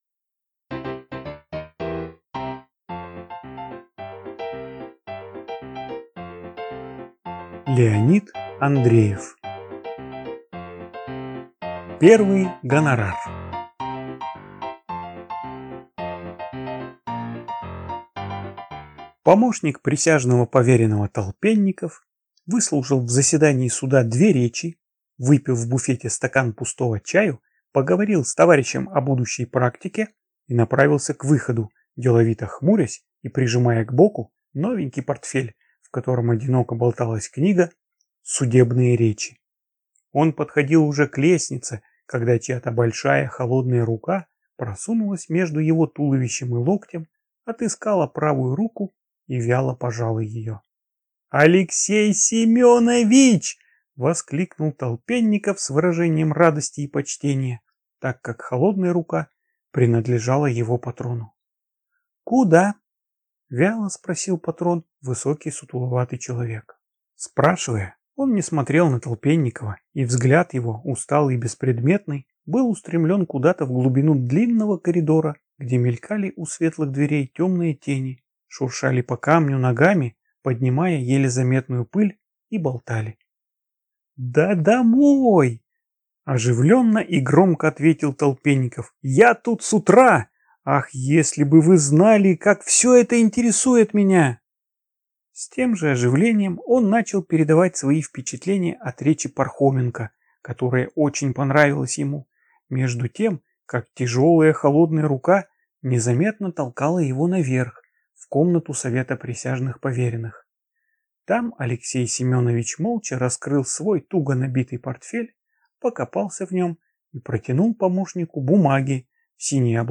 Аудиокнига Первый гонорар | Библиотека аудиокниг